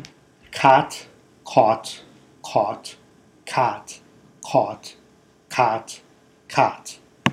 And here’s one with no context if you want to take the quiz and identify which are “cots” and which are “caughts.”
Yes, I can clearly hear two different sounds there. But it’s an artificial environment–words don’t sound the same when there is a special emphasis on clear articulation.
cought.m4a